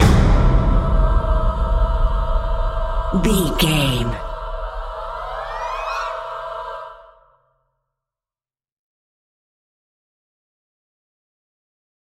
Vocal Clusters High With Hit.
Atonal
scary
tension
ominous
dark
suspense
disturbing
haunting
eerie
drums
percussion
vocals
horror